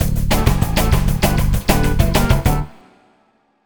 Swinging 60s 3 Ful-D.wav